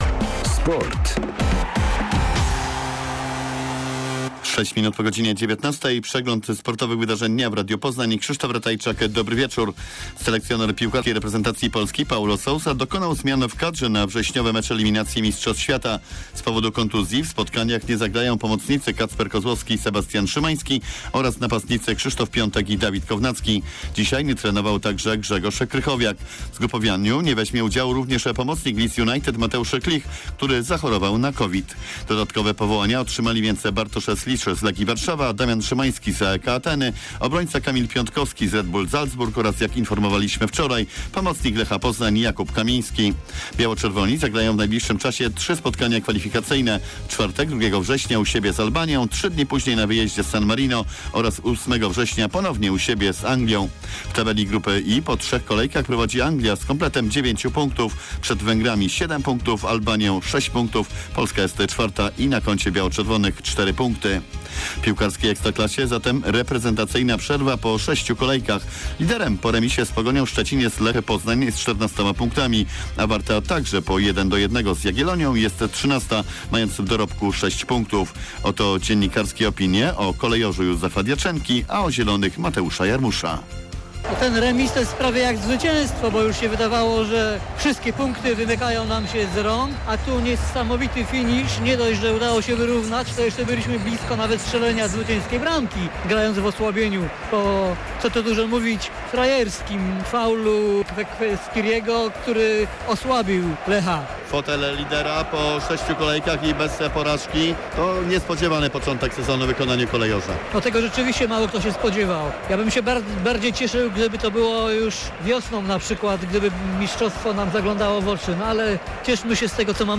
30.08.2021 SERWIS SPORTOWY GODZ. 19:05